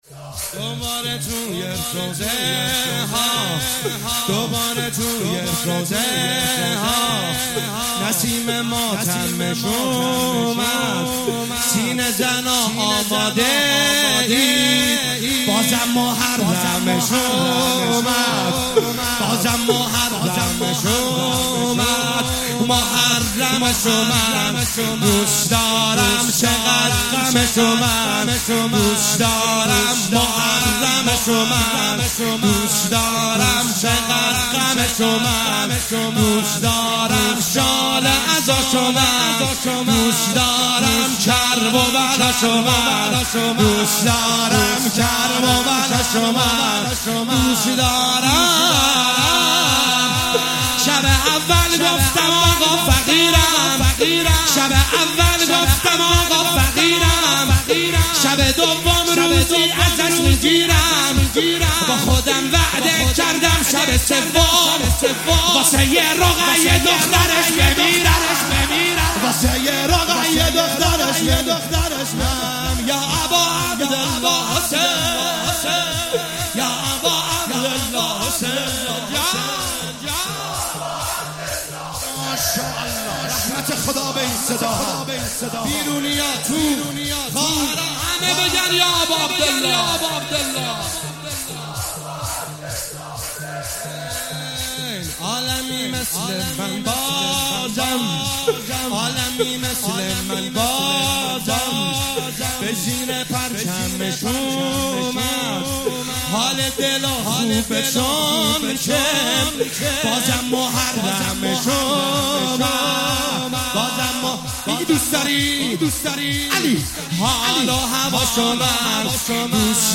محرم1401شب دوم -شور- دوباره توی روضهها
محرم1401 شب دوم